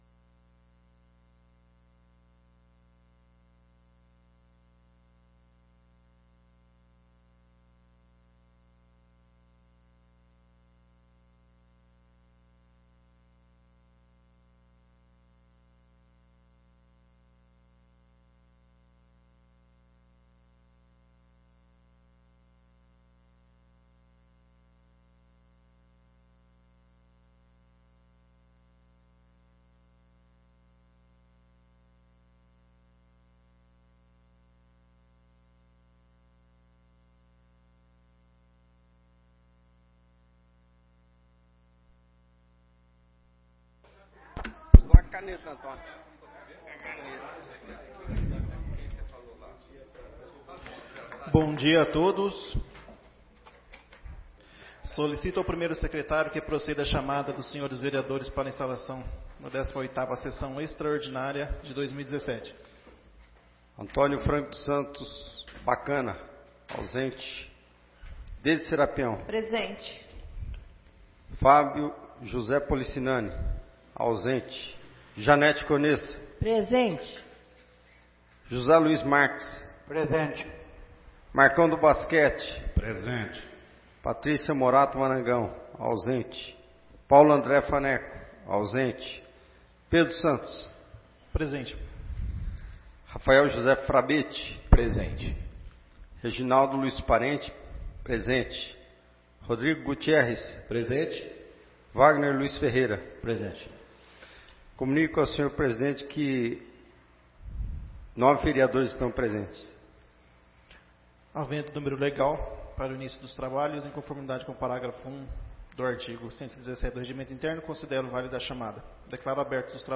18ª Sessão Extraordinária de 2017